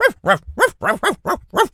dog_small_bark_seq_01.wav